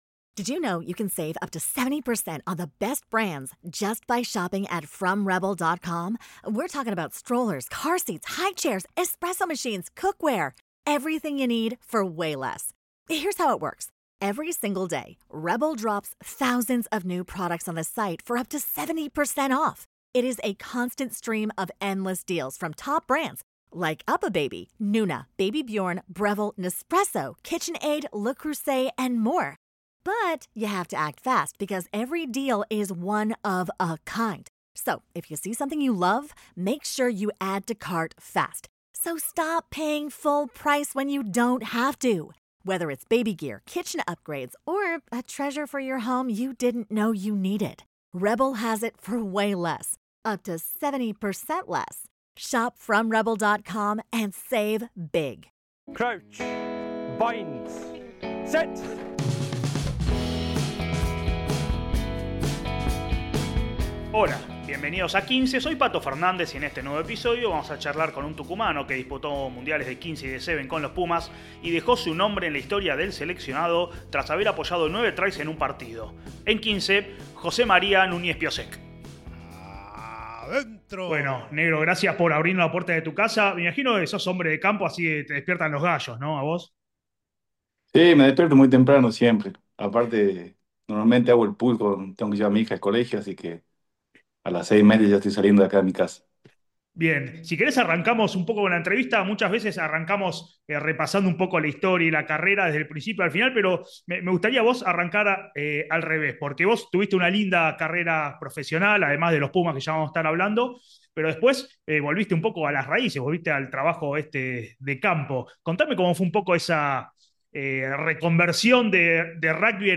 ¡Charlas de rugby con los protagonistas!